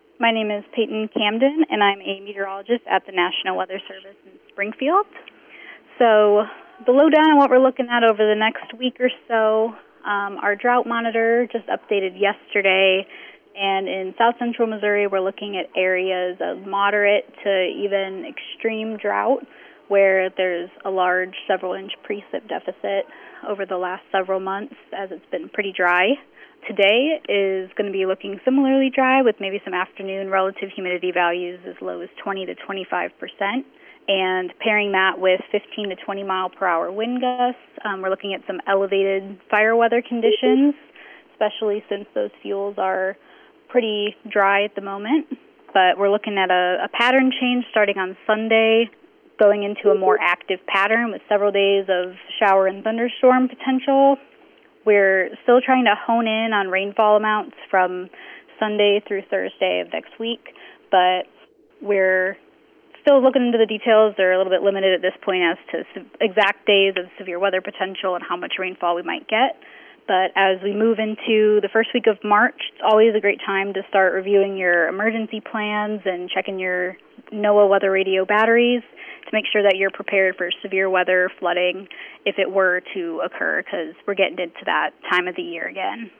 meteorologist with the National Weather Service gives us an idea of what to expect over the next week: